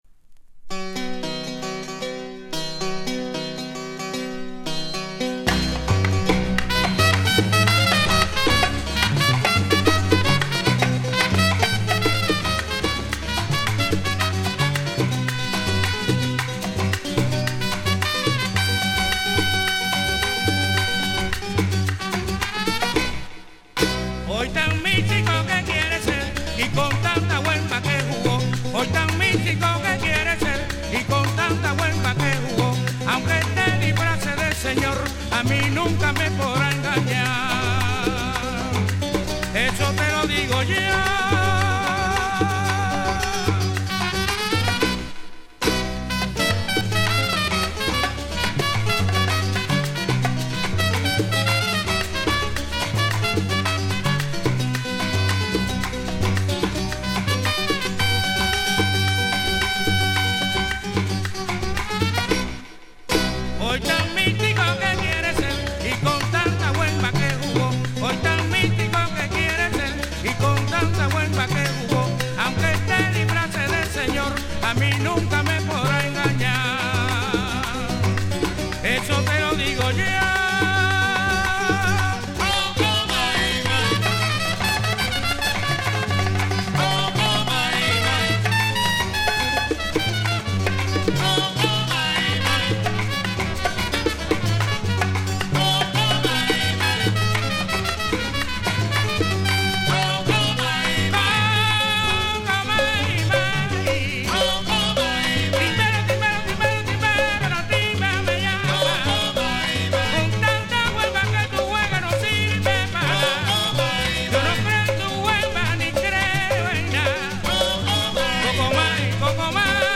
CONDITION見た目VG+,音はVG+(+) / VG+(+) (経年劣化) (VINYL/JACKET)
極上の ソン Son ,　ワラーチャ Guaracha ,　ワワンコー　Guaguancoが収録されている。